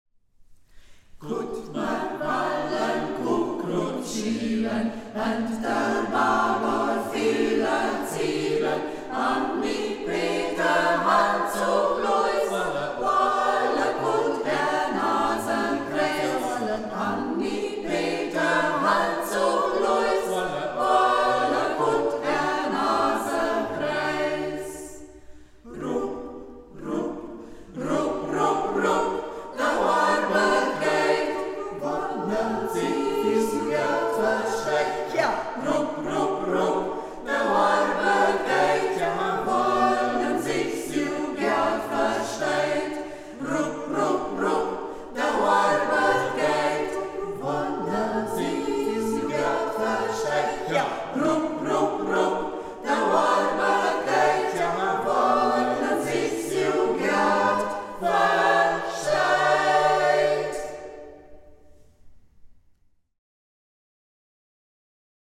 Singkreis Kampestweinkel • Ortsmundart: Braller • 1:05 Minuten • Herunterladen